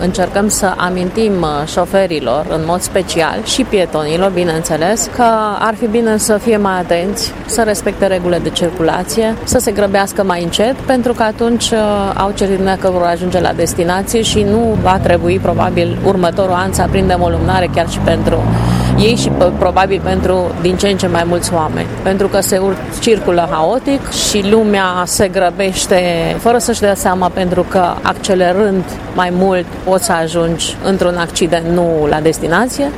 Inspectoratul de Poliţie Judeţean Mureş a organizat aseară o manifestare dedicată memoriei victimelor decedate în accidente rutiere.